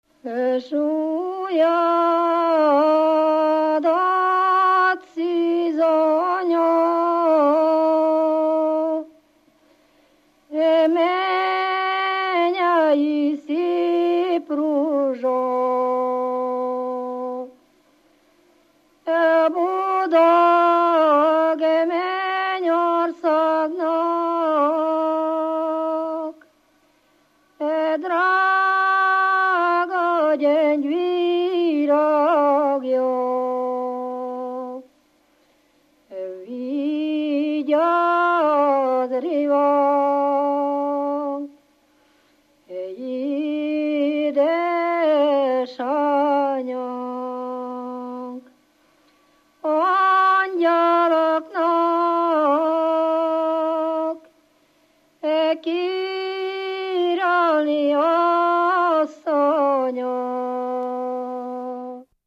Moldva és Bukovina - Moldva - Klézse
ének
Stílus: 4. Sirató stílusú dallamok